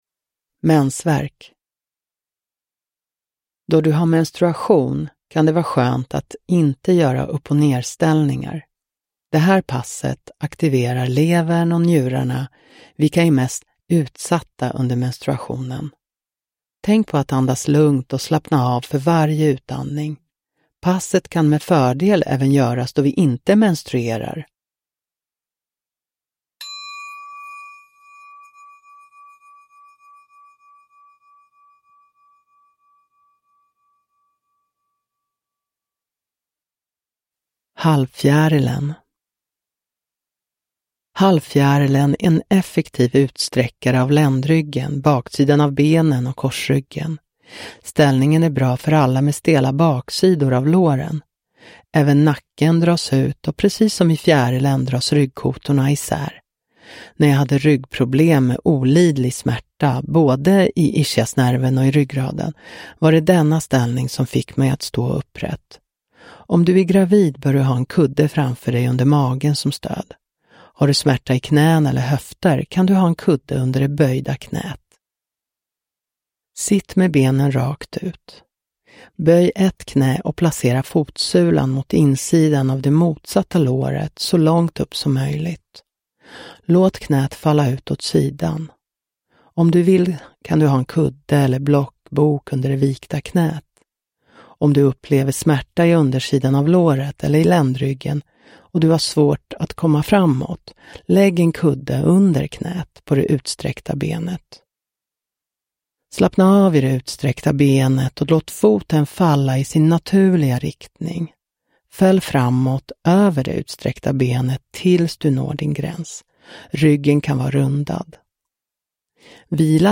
Mensvärk – Ljudbok – Laddas ner